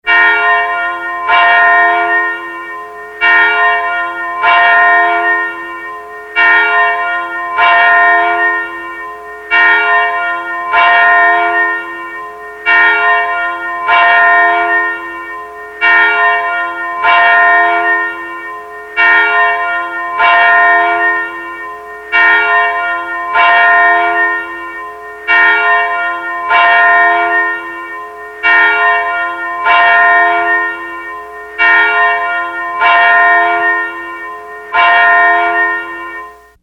Alarm bell sound effect ringtone free download
Sound Effects